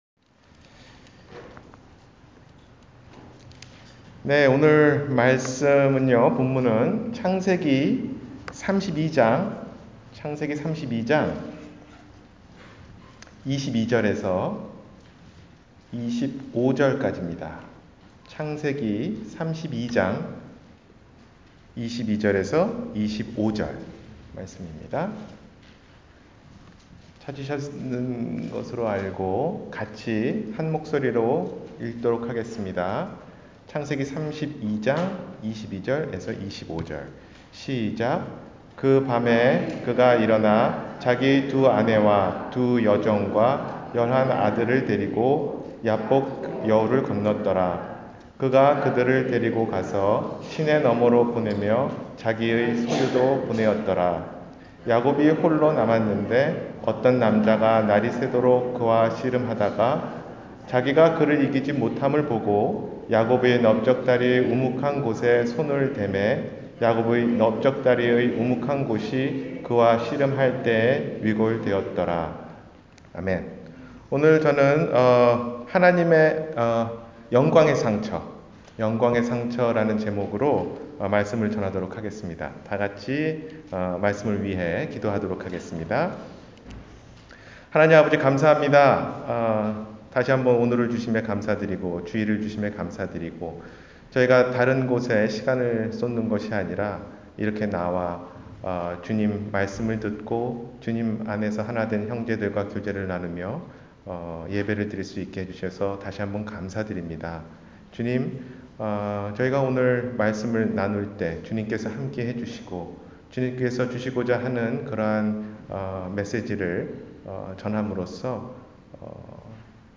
영광의 상처 – 주일설교